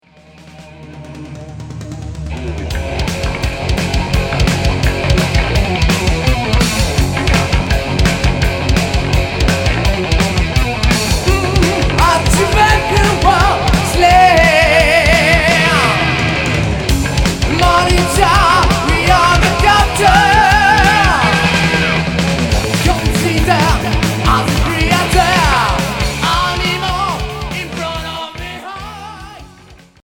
Heavy métal Unique 45t retour à l'accueil